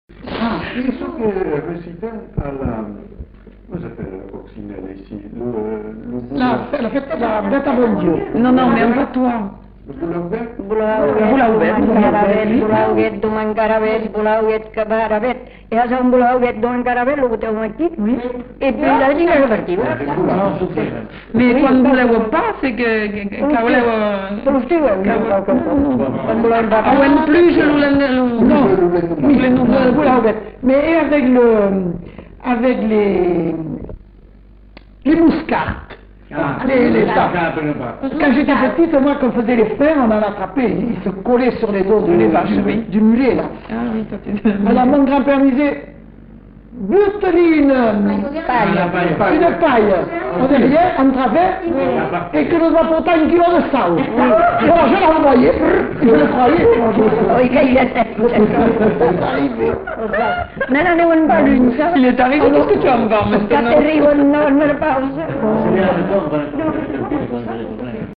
Lieu : Villandraut
Effectif : 1
Type de voix : voix de femme
Production du son : récité
Classification : formulette enfantine